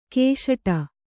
♪ kēśaṭa